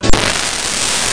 CHORD.mp3